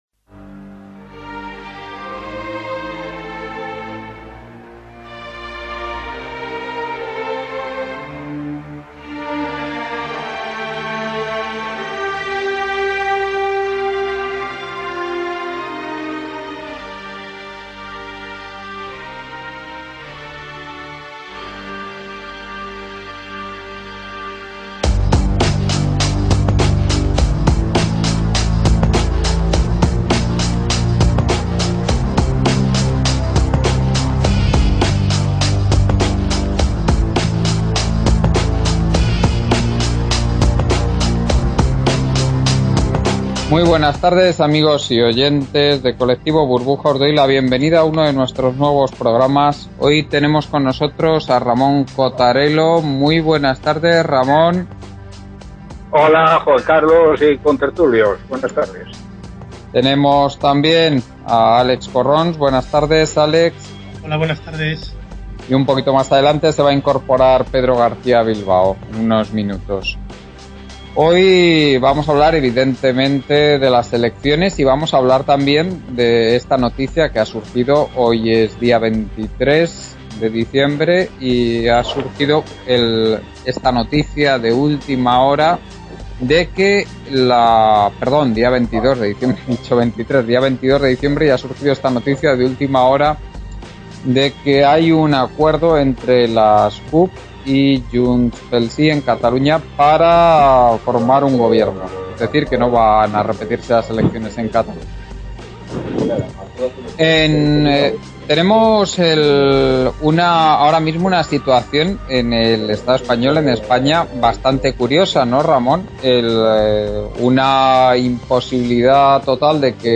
Debate radio Colectivo Burbuja – Tras las elecciones.